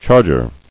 [charg·er]